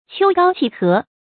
秋高氣和 注音： ㄑㄧㄡ ㄍㄠ ㄑㄧˋ ㄏㄜˊ 讀音讀法： 意思解釋： 形容秋空高朗、天氣晴和。